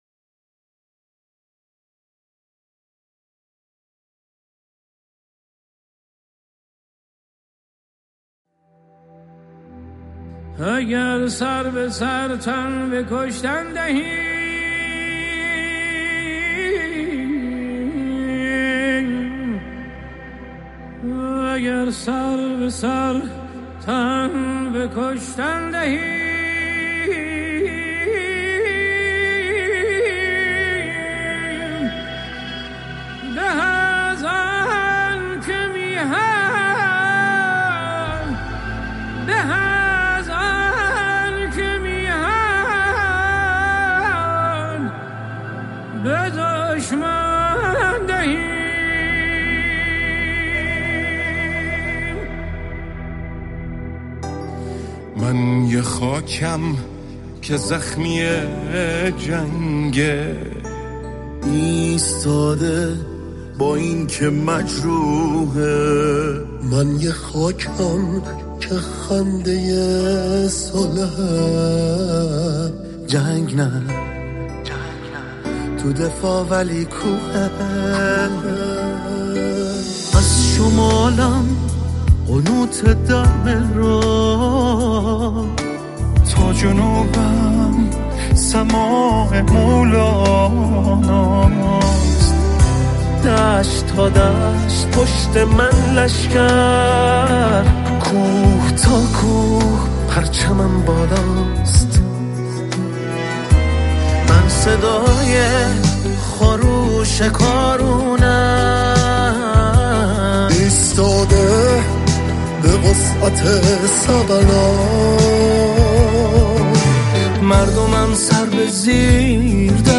همه سولیست بوده‌اند و كُرال‌خوان نبودند
موسیقی پاپ